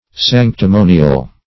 Search Result for " sanctimonial" : The Collaborative International Dictionary of English v.0.48: Sanctimonial \Sanc`ti*mo"ni*al\, a. [Cf. LL. sanctimonialis.